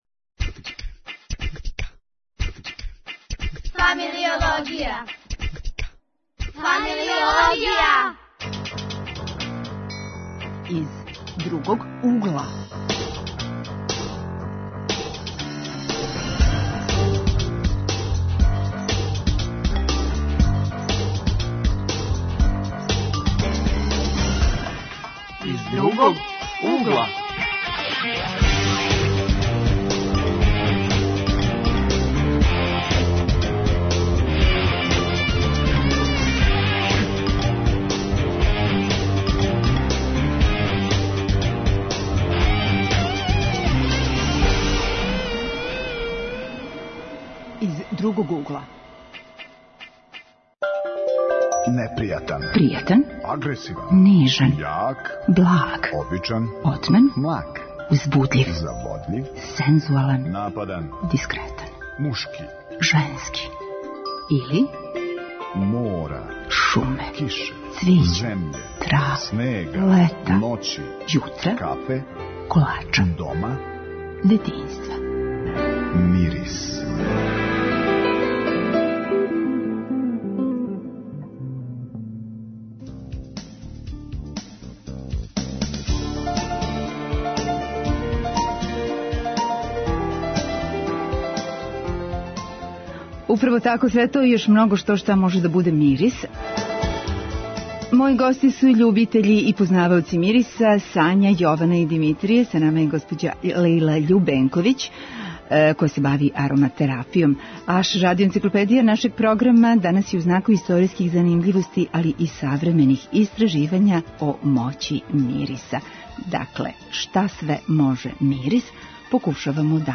Гости су студенти